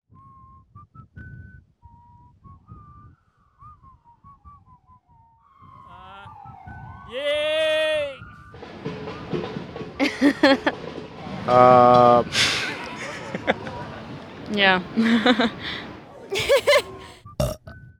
The stomach is a series of sounds, the pauses and laughs that were caught amongst speech.
Stomach.wav